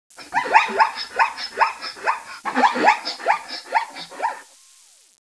zebra.wav